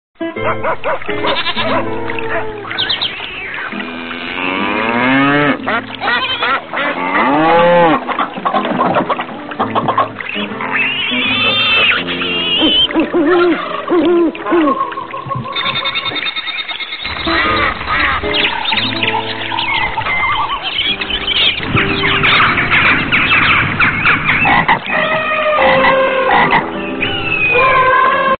صدای حیوانات